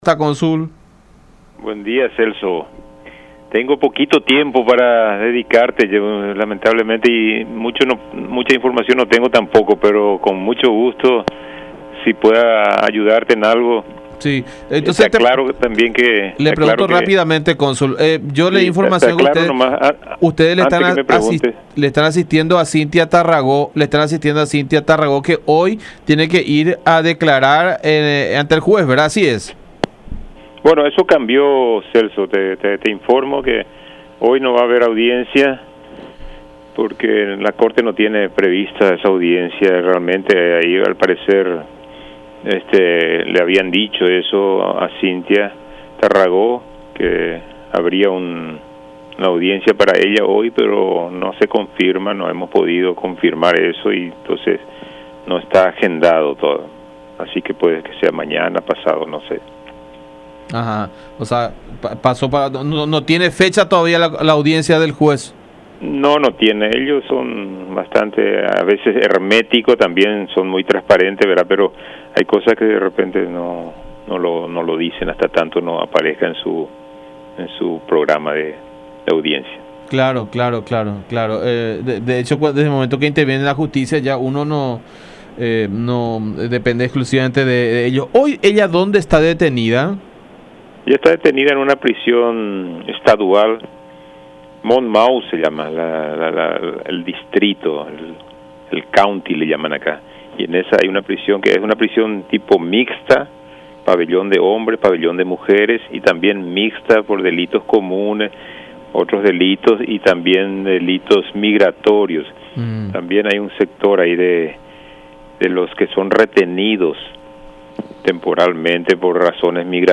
“No tiene fecha la audiencia ante el juez. Ellos (estadounidenses) son bastante herméticos en ese sentido”, manifestó Juan Buffa, cónsul paraguayo en Nueva York, en diálogo con La Unión.